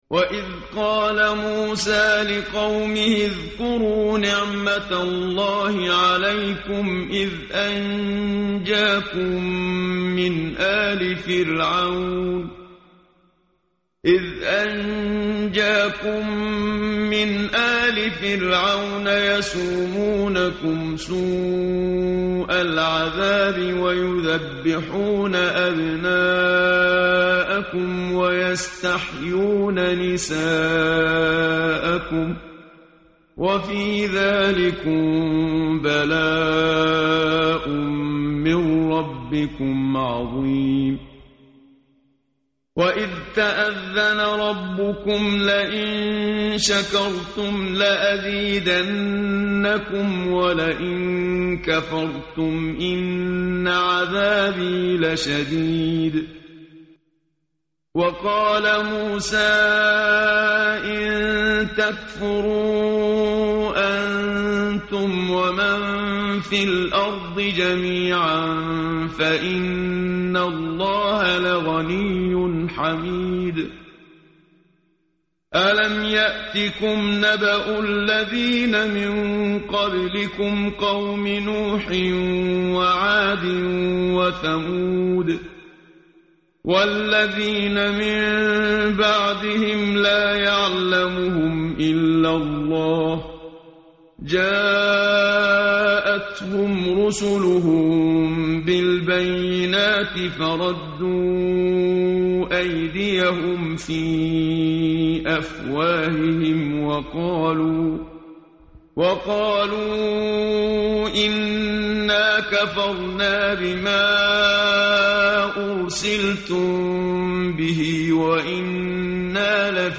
tartil_menshavi_page_256.mp3